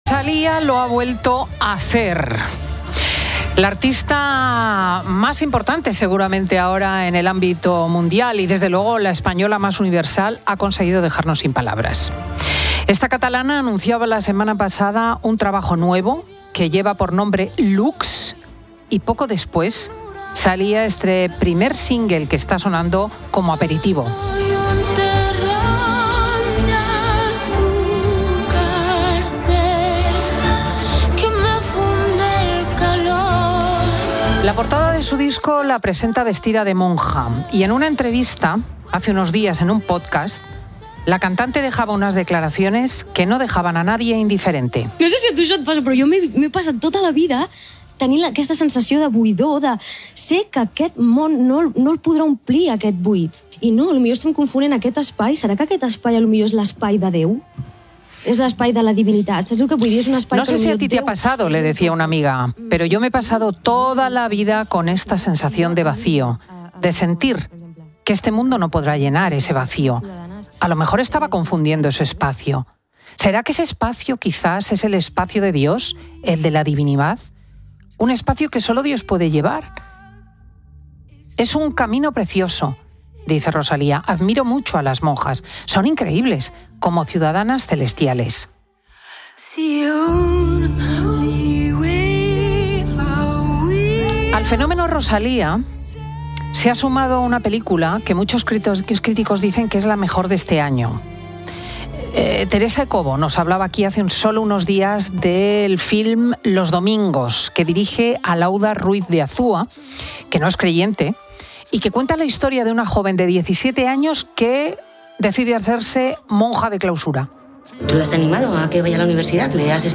entrevista monja